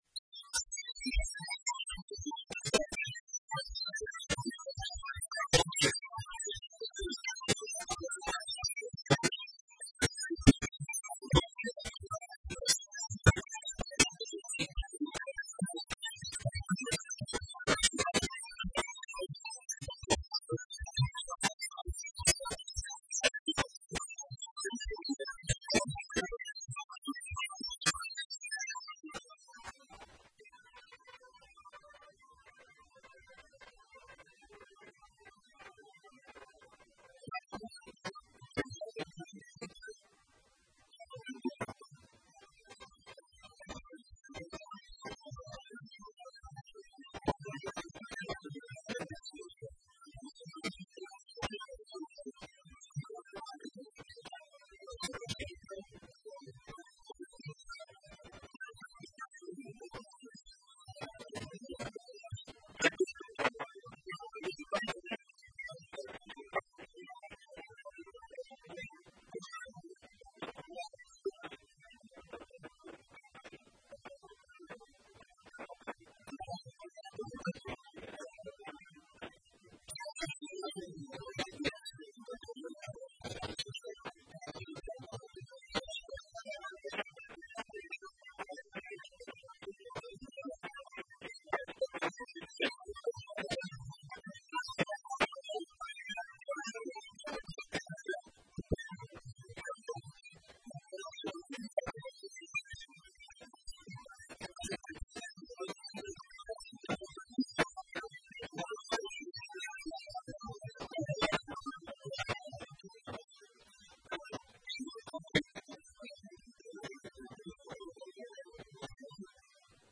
Análisis Político - Momento Grapa como Titularon los Oyentes :: Radio Federal Bolívar